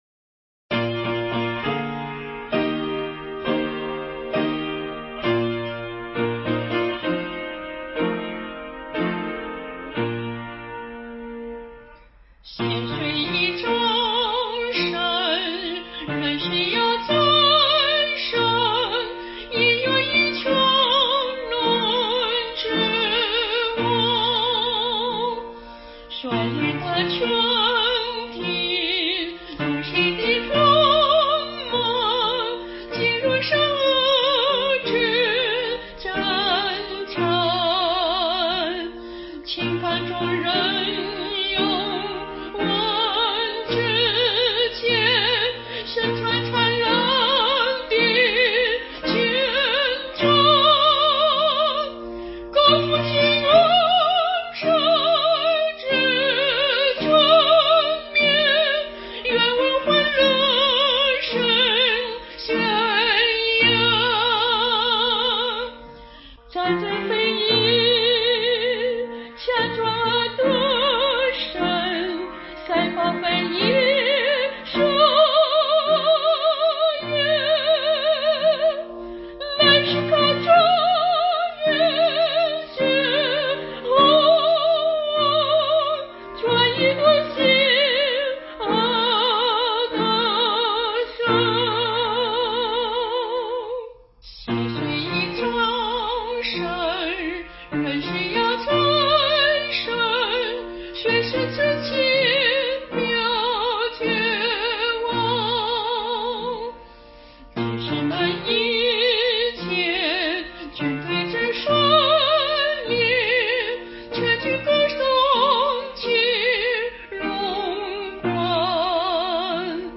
伴奏
这首庄严雄峻的合唱诗歌描述了基督徒所从事的战争。